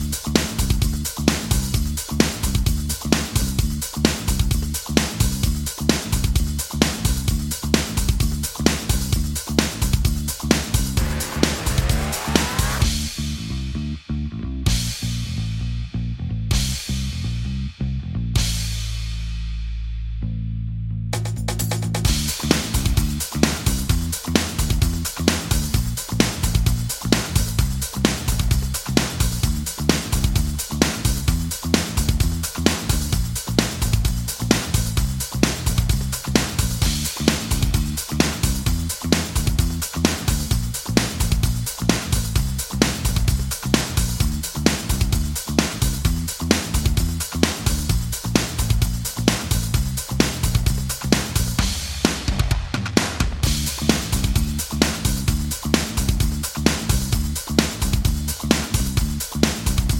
Minus Main Guitars For Guitarists 5:01 Buy £1.50